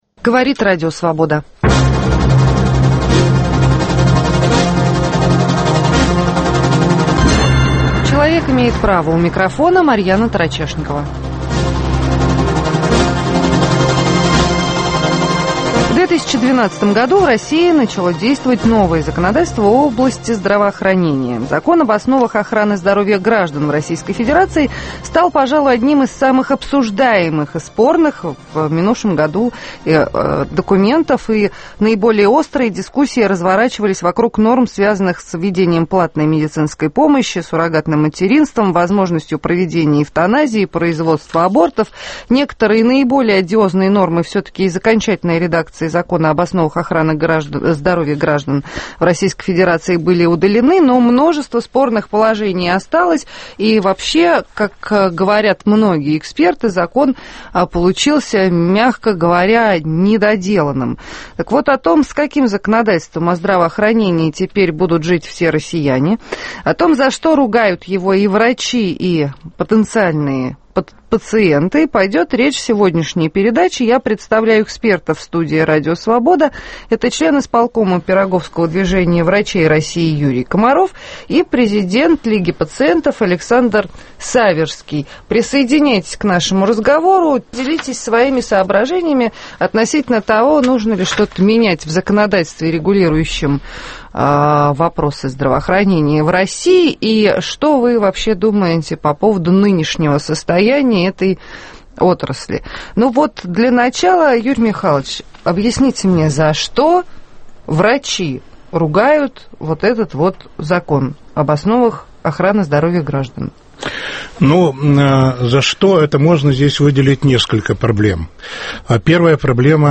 (Повтор)